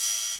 TM88 - OPEN HAT (9).wav